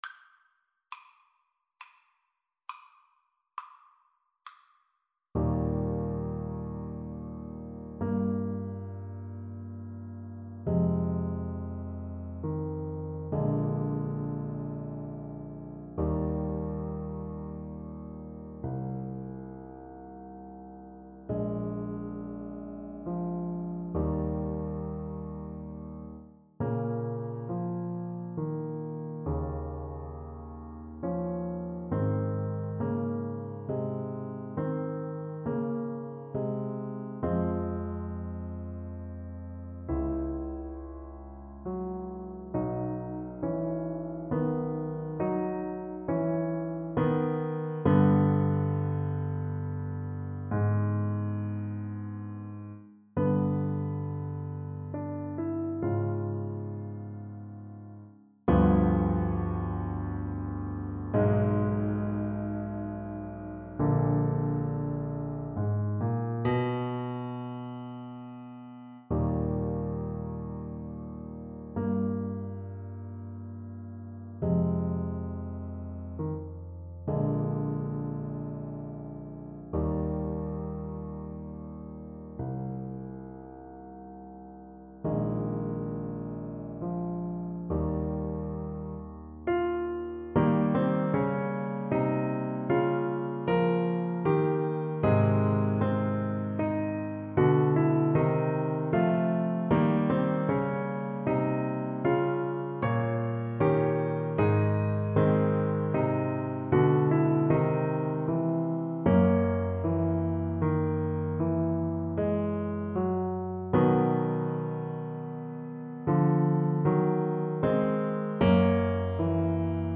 Classical
Score Key: Eb major (Sounding Pitch)
Time Signature: 6/4